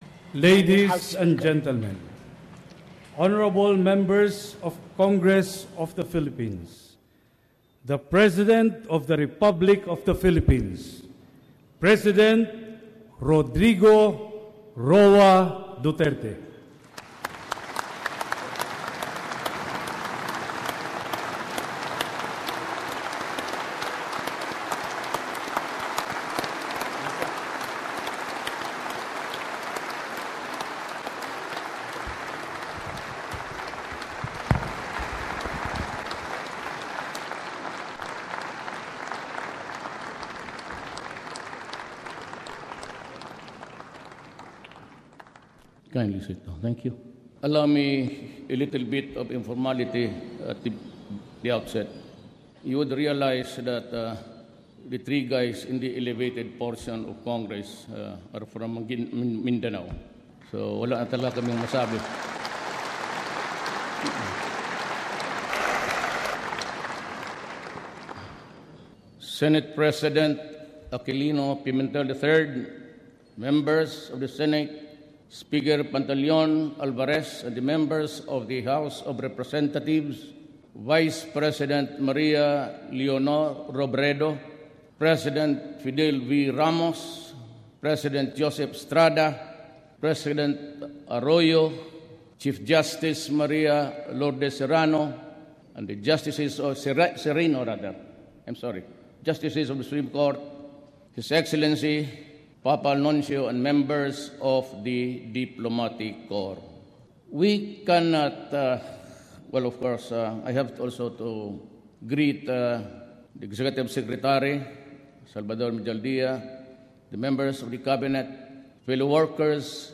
During the joint session of the Senate and Congress for the 17th Congress of the Philippines, President Rodrigo Duterte delivered his first State of the Nation Address.
Image: President Rodrigo Duterte in his first SONA (Screenshot from Rappler) (Full text of the one hour and 40 minutes first SONA of President Duterte delivered in English, with some ad-libs in Filipino and a bit of Bisaya.)